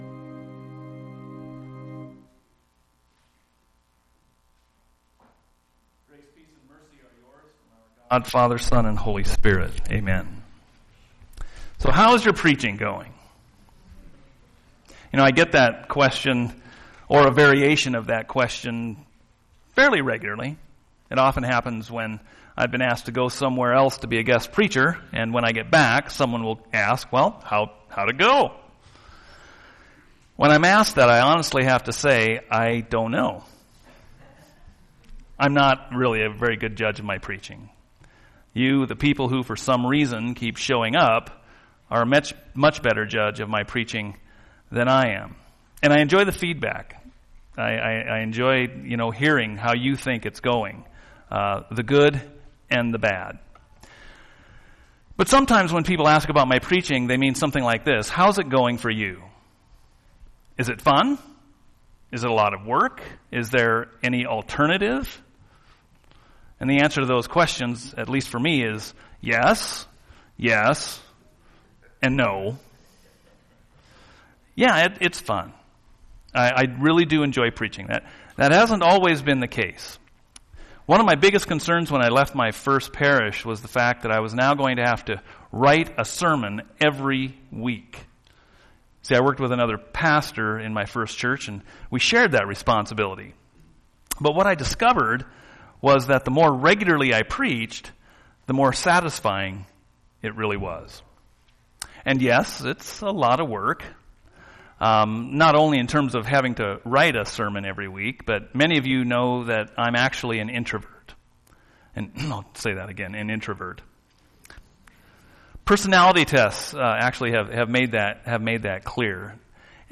Sunday Service Sermons